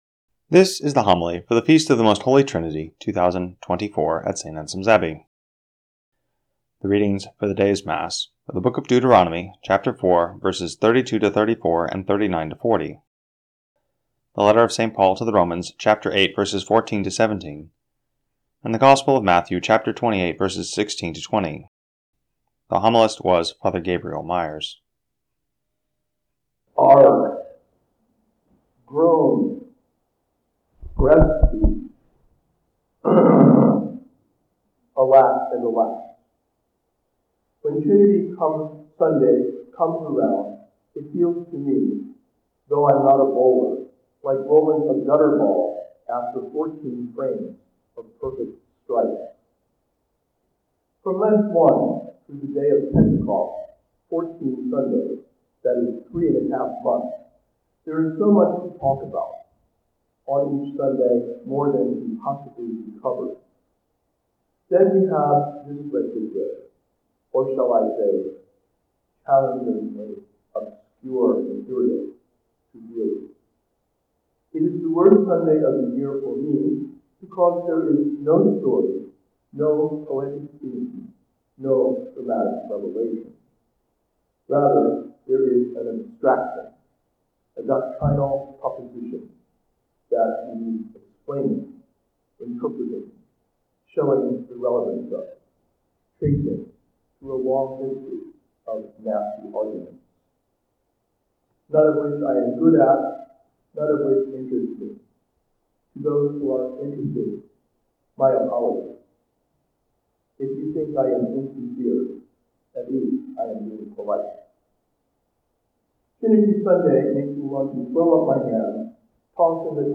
2025 Homilies The Most Holy Body and Blood of Jesus Christ June 22